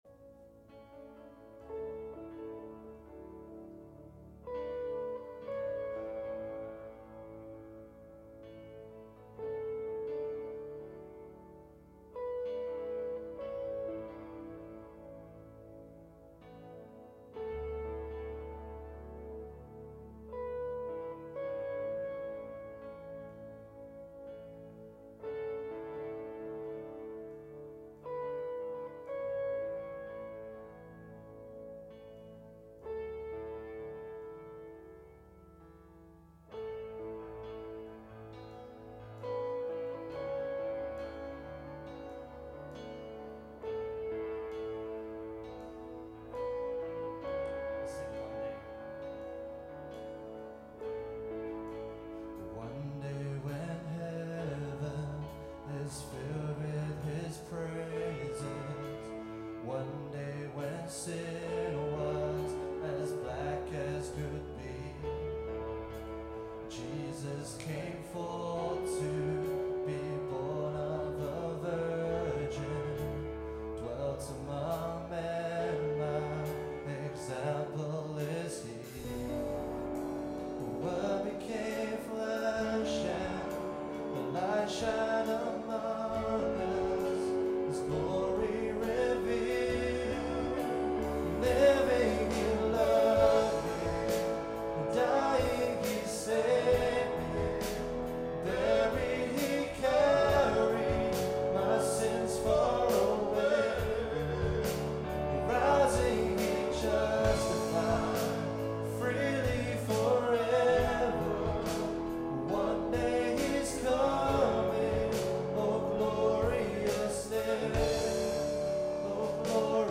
Worship April 5, 2015 – Birmingham Chinese Evangelical Church
Lead/Guitar
Vocals
Keys
Bass
E.Guitar
Drums